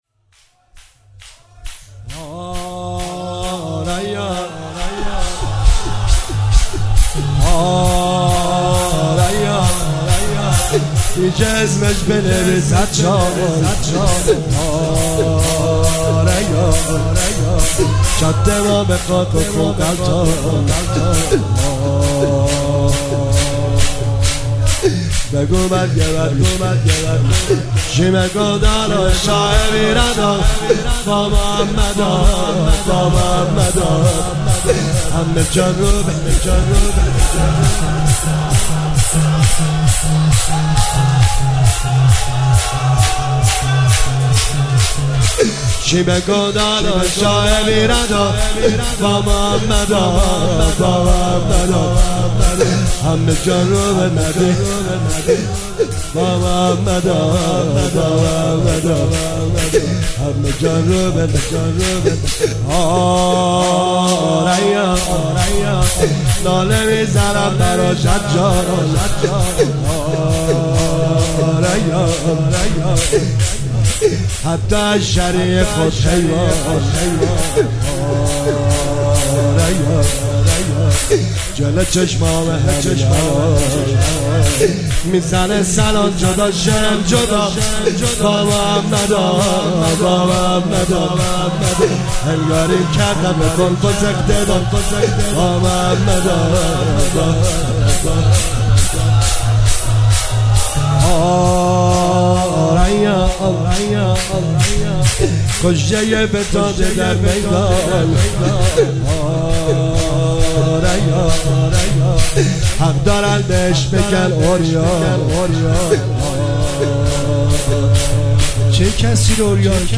مداحی شور هیئت هفتگی